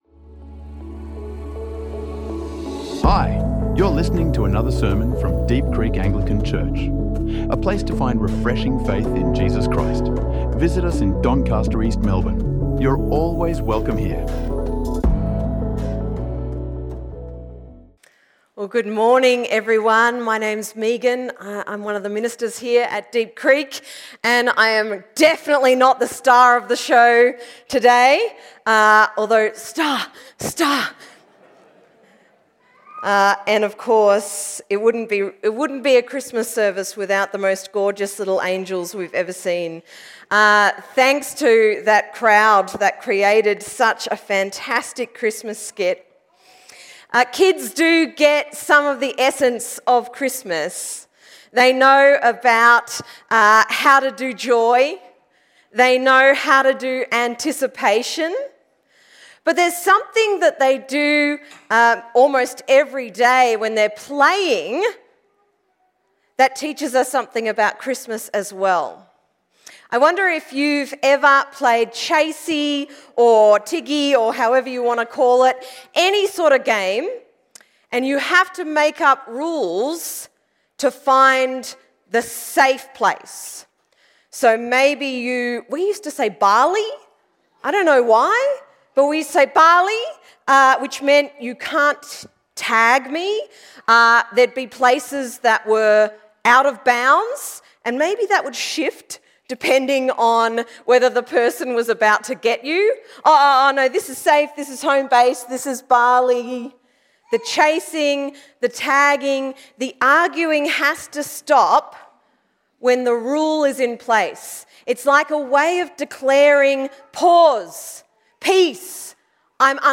Peace Beyond Expectation | Sermons | Deep Creek Anglican Church